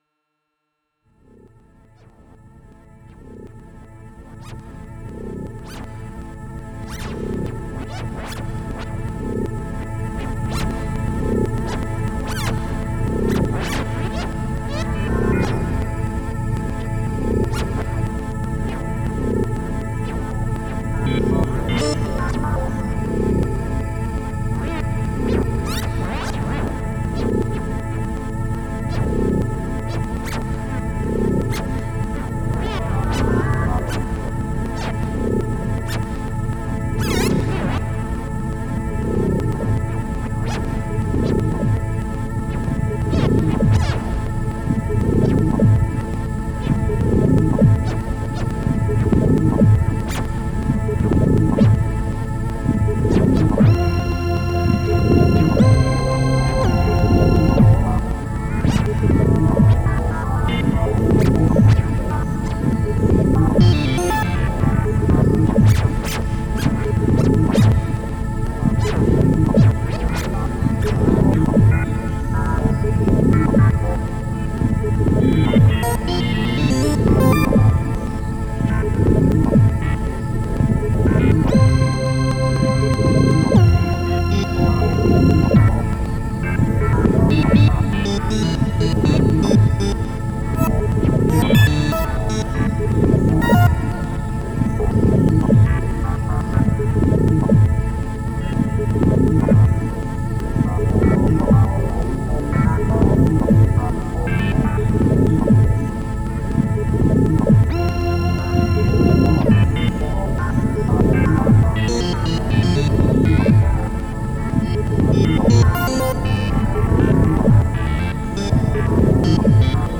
джемчик-атмосферка squid (bank 55), plaits (низ), marbles на рандоме (квантайзер еще едет, а забивать лад в марблз лениво было)) voltage в статике в роли клавиатуры Вложения squid atmo.mp3 squid atmo.mp3 11,5 MB · Просмотры: 637